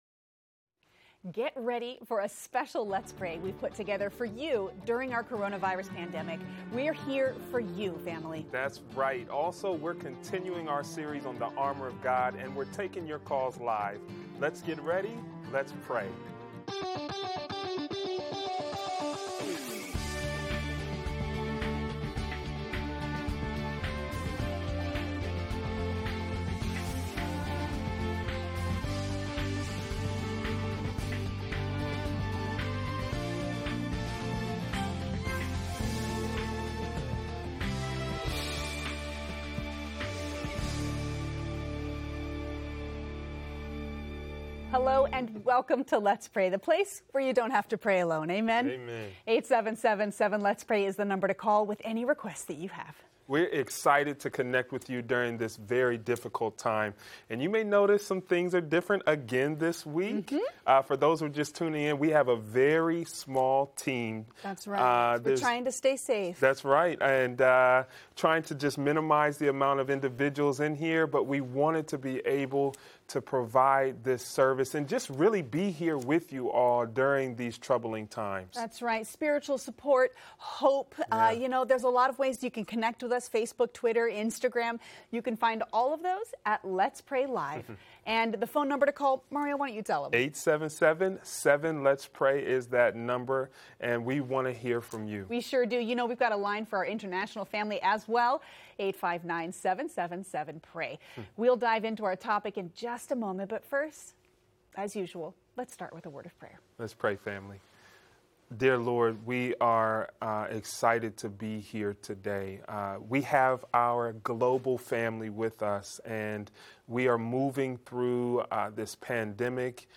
We’re continuing our series on the Armor of God and we’re taking your calls live.